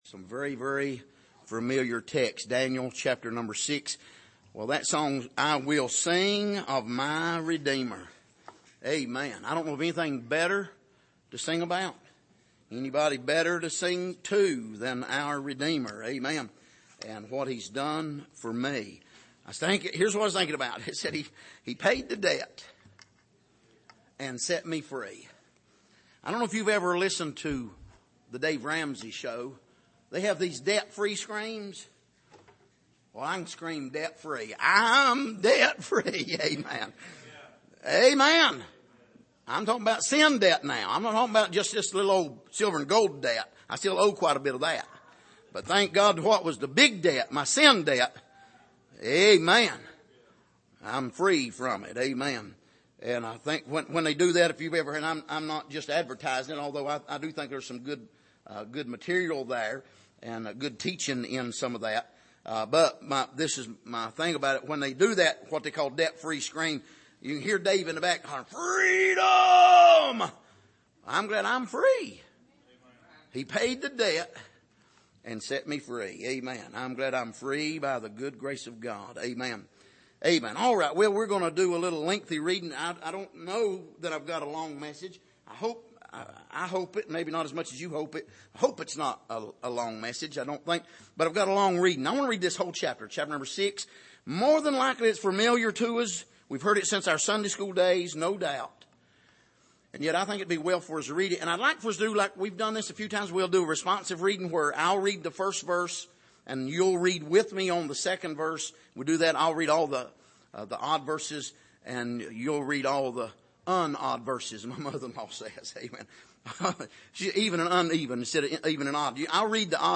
Daniel 6:19-20 Service: Sunday Morning What Will Your Answer Be?